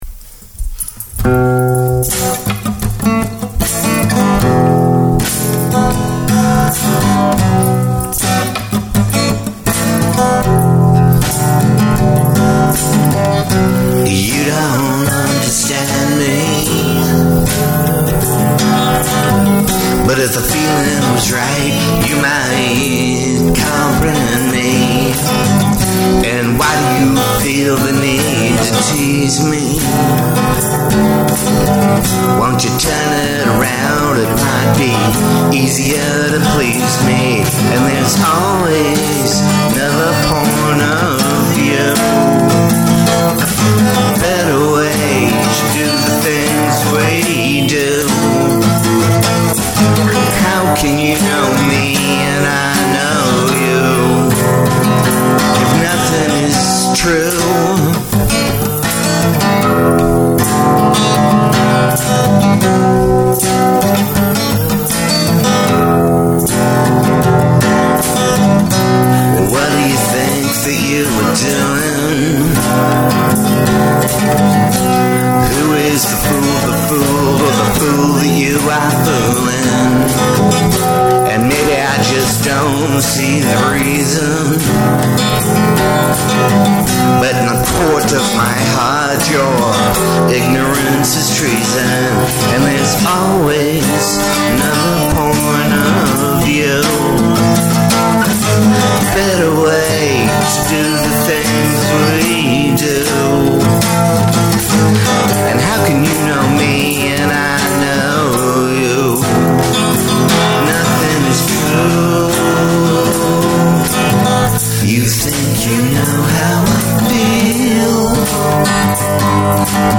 (I dunno..feeling insecure on the vocals on this one…)